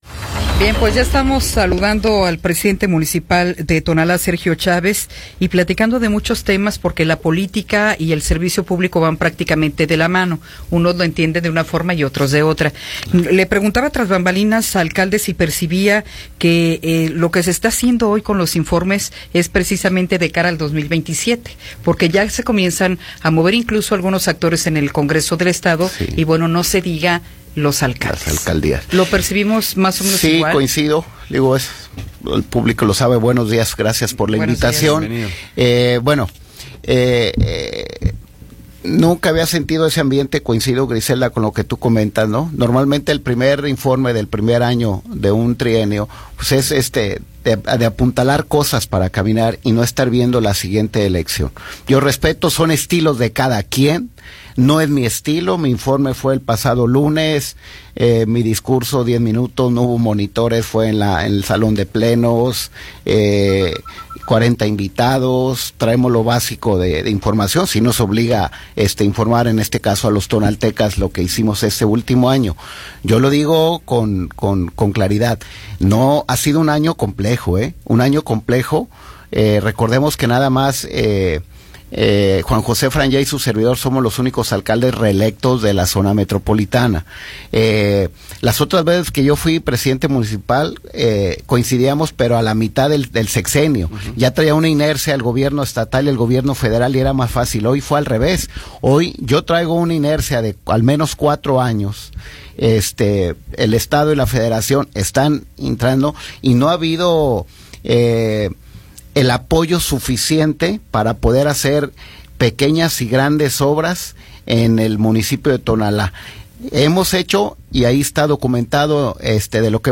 Entrevista con Sergio Chávez Dávalos